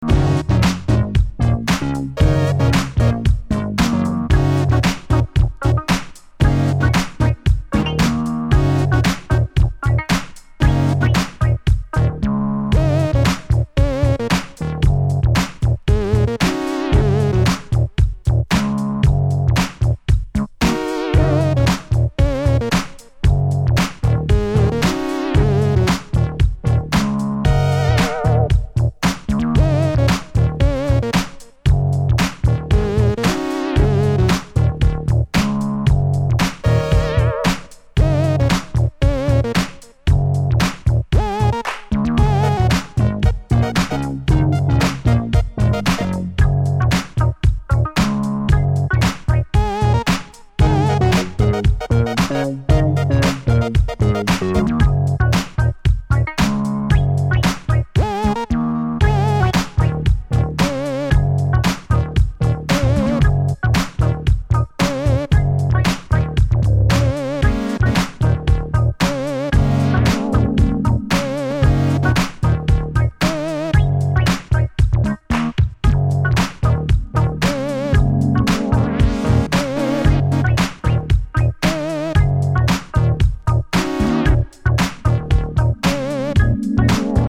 sizzling, floor-busting electro-funk
The flip features 3 original funk grooves of varying tempo.
House Funk